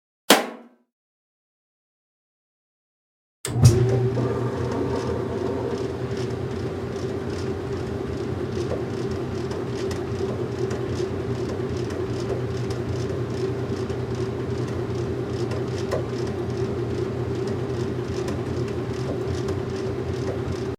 Washing Machine Start And Run
SFX
Washing Machine Start and Run.mp3